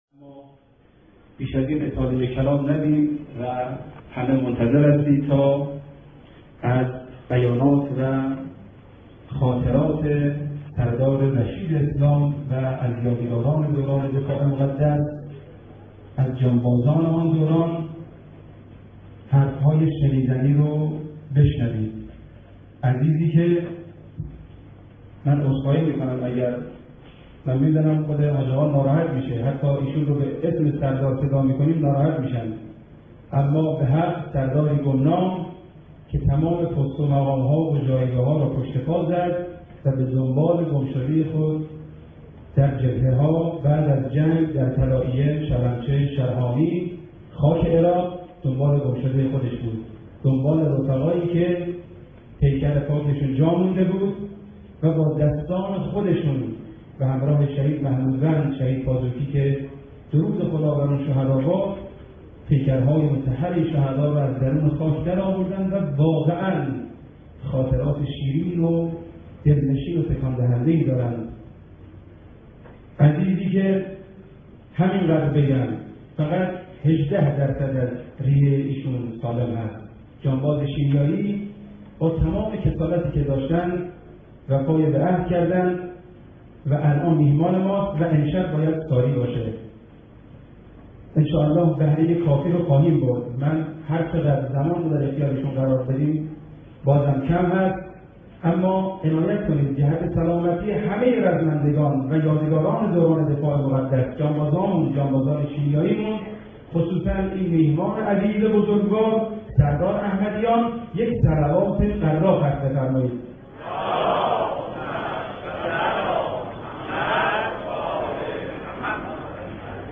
دسته بندی صوت‌ها بی سیم بیانات بزرگان پادکست روایتگری سرود شرح عملیات صوت شهدا کتاب گویا مداحی موسیقی موسیقی فیلم وصیت نامه شهدا گلف چند رسانه‌ای صوت روایتگری آی شهدا به داد دل ما برسید ..